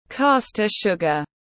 Đường xay tiếng anh gọi là caster sugar, phiên âm tiếng anh đọc là /ˈkɑː.stə ˌʃʊɡ.ər/
Caster sugar /ˈkɑː.stə ˌʃʊɡ.ər/